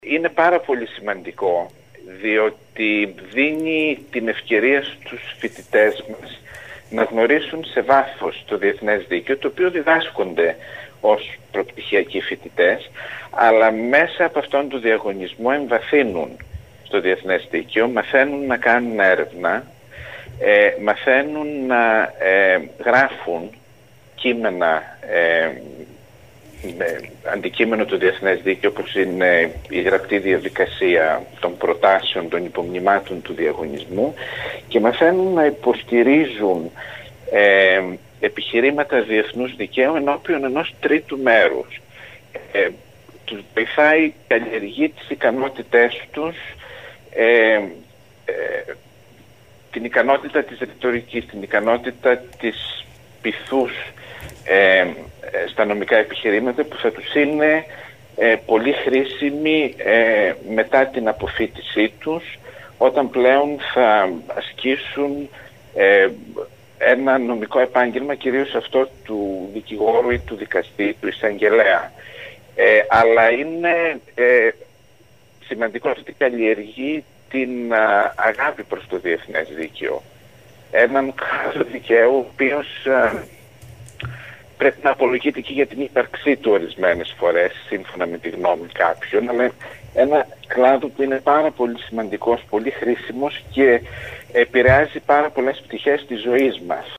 Με τηλεφωνική του  παρέμβαση